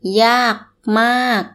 ∧ yaag ∧ maag